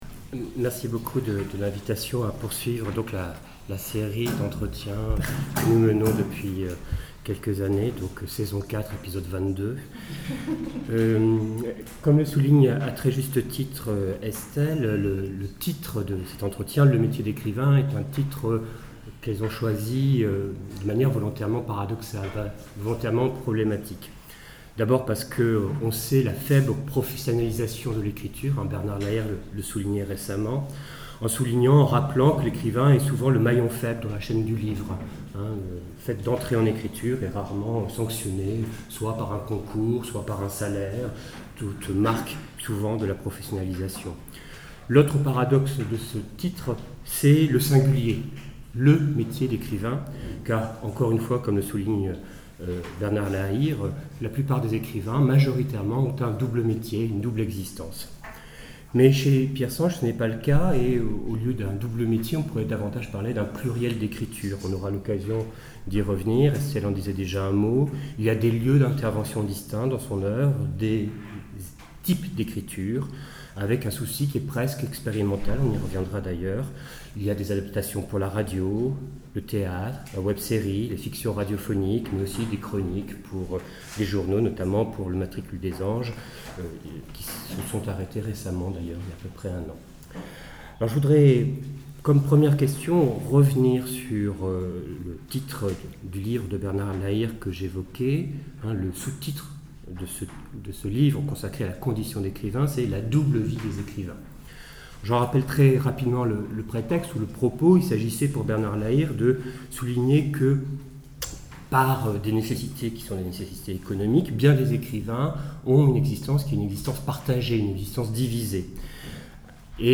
Les métiers de l’écrivain (entretien)